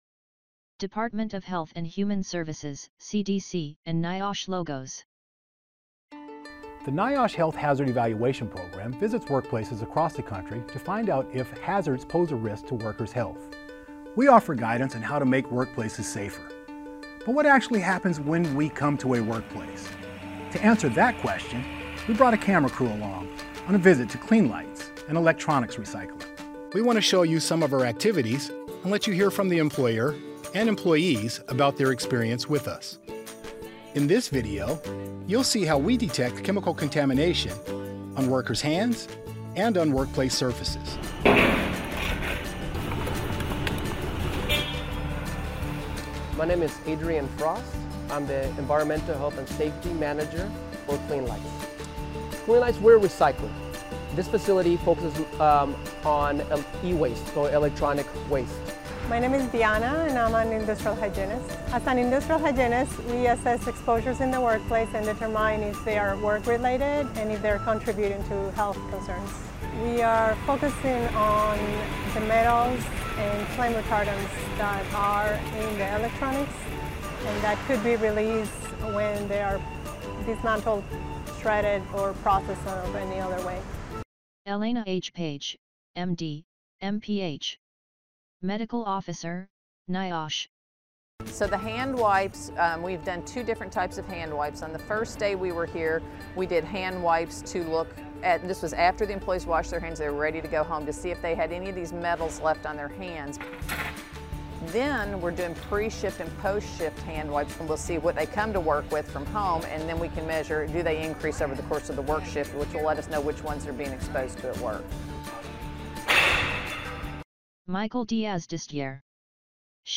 HHE-Measuring-Contaminants-on-Wrkrs-Skin-and-Wrkplc-Surfaces_3.8.23_AudioDescription.mp3